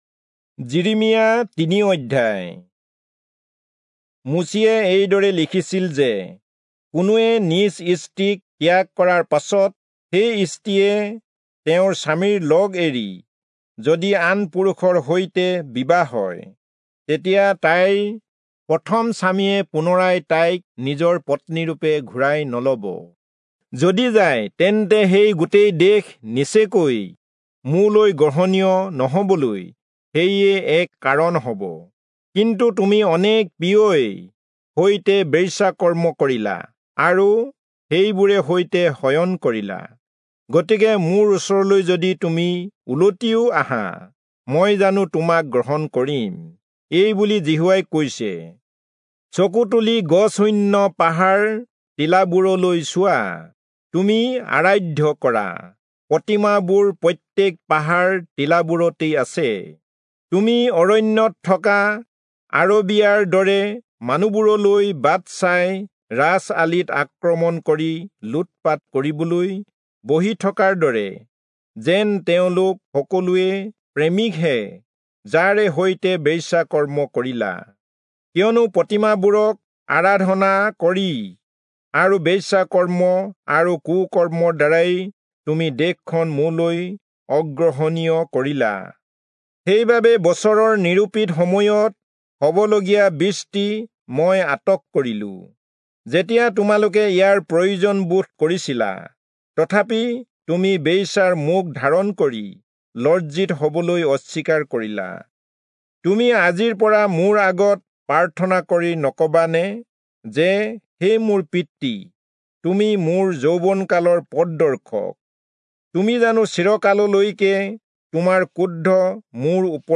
Assamese Audio Bible - Jeremiah 39 in Hcsb bible version